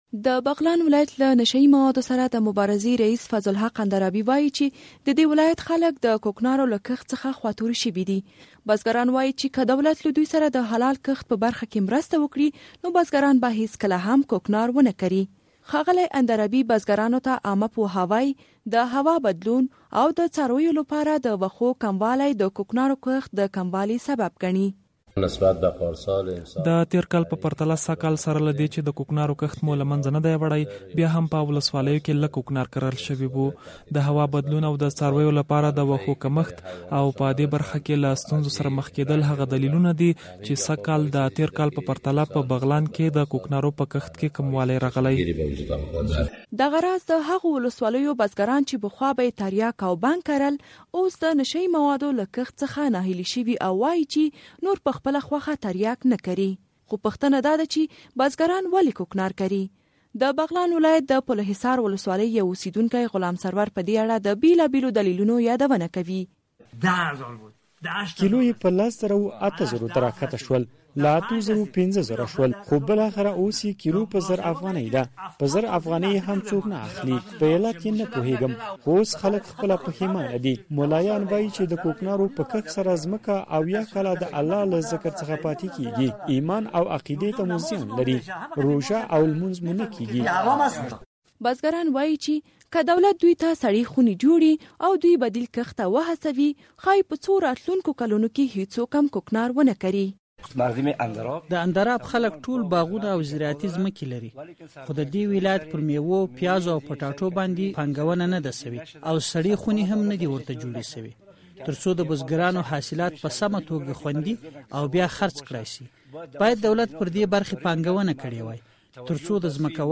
د بغلان راپور